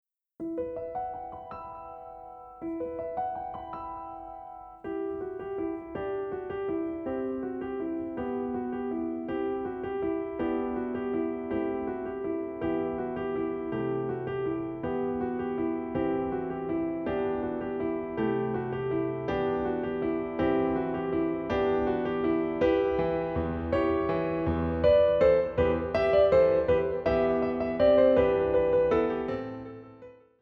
Voicing: PVG Collection